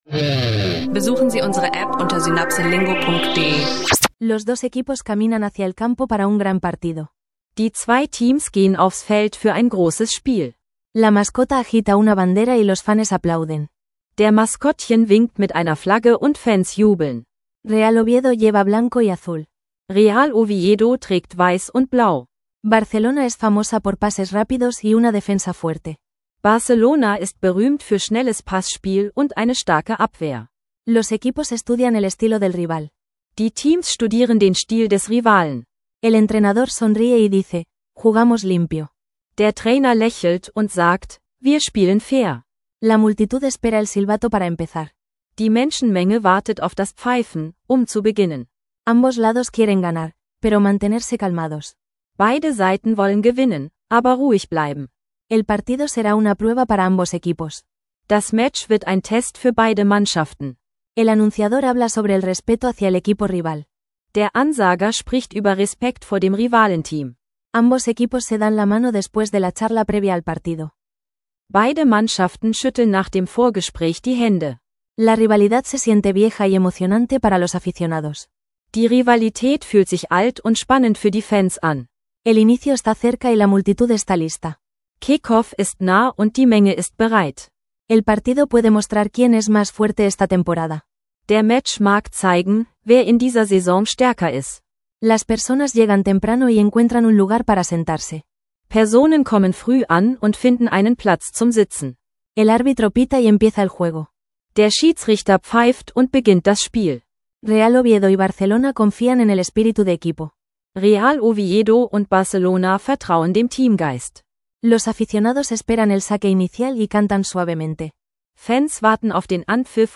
Eine kompakte Vorlese-Story über Rivalität im Fußball kombiniert mit Kultur-Themen – ideal für Spanisch lernen mit Podcast